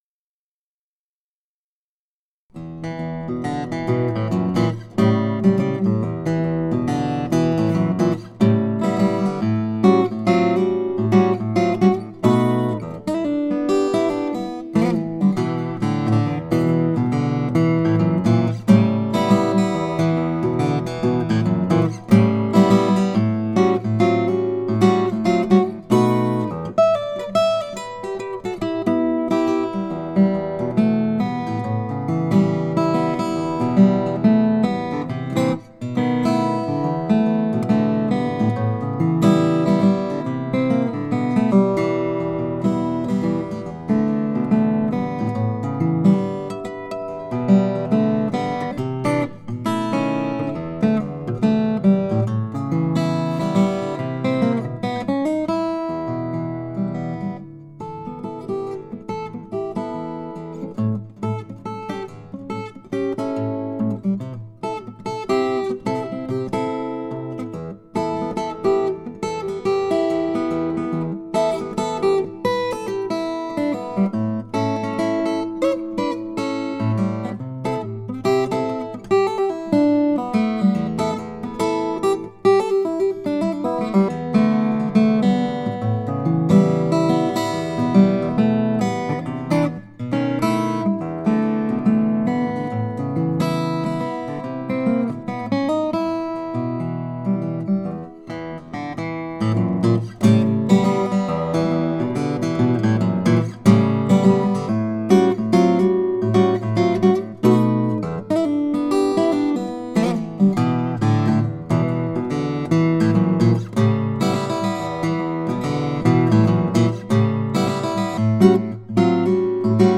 Вид моей акустической блюзовой мелодии на Collings C10 Deluxe.
Люблю звуки блюза !!!
Так много разной динамики происходит. Отличный паз тоже!
Запись довольно приличная но не так хорошо, как хотелось бы, естественно. Подобные пьесы с определенным количеством энергии и скорости, а также смесью пробежек, плотных аккордов и штурма, являются самыми трудными для меня, чтобы записать, что в некоторых местах мелодии микрофоны будут звучать лучше в месте X и в других местах места Y. Может быть, еще немного поработать над акустическими свойствами помещения, чтобы выровнять ситуацию.